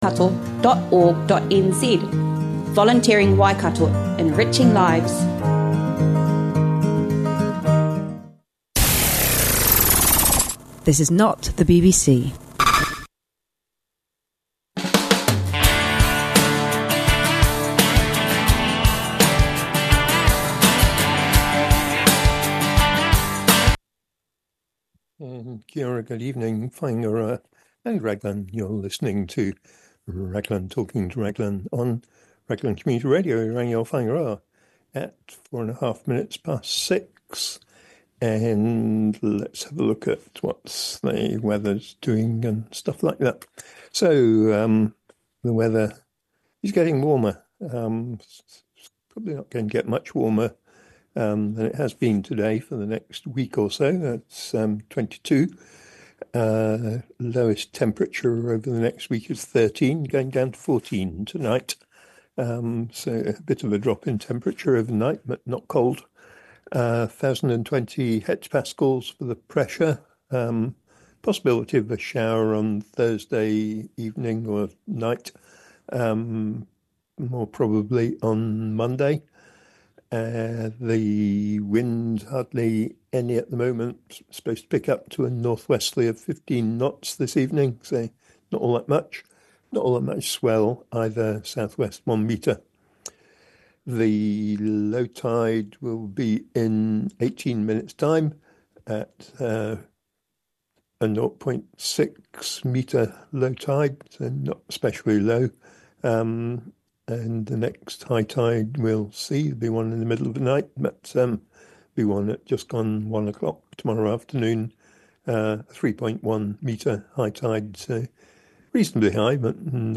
There's a Community Board meeting tomorrow, so a look at some of the things on that agenda, including aquaculture and a resource consent application for spat catching, a cycleway being extended at one end, but not the other, the lack of action from Waka Kotahi to allow bike racks on buses to be used again, the signs at the wharf about fishing and mention of a council meeting this morning, which revealed that an application to Fast Track the sewage consent didn't make it onto the government's list. Also Morning Show interviews with the chair of the Community Board and with Xtreme, including the problem of how to keep the litter bins from overflowing over the holiday period.